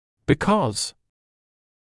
[bɪ’kɔz][би’коз]потому что, так как, поскольку